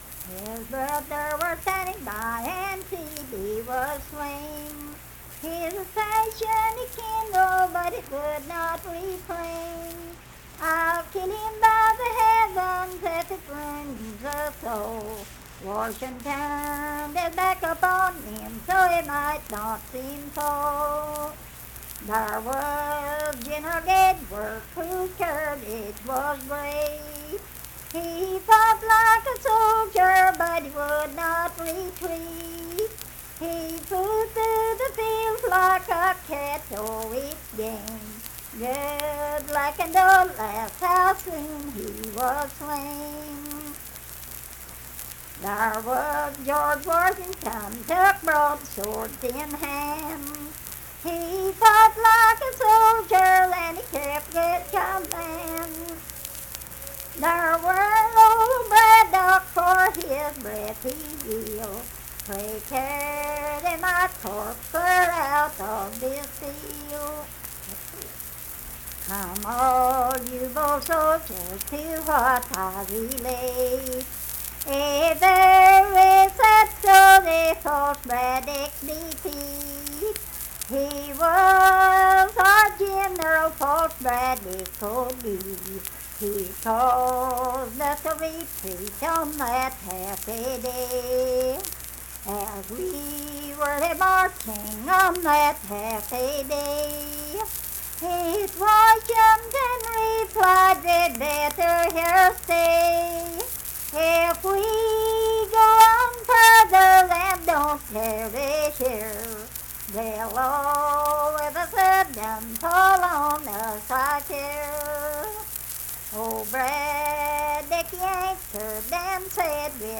Unaccompanied vocal music performance
Verse-refrain 10(4).
Voice (sung)